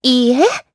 Erze-Vox-Deny_jp.wav